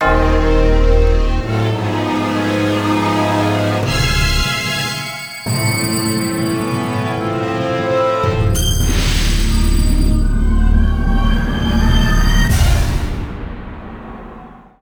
Pasaje de suspense. Orquesta.
suspense
orquesta
Sonidos: Música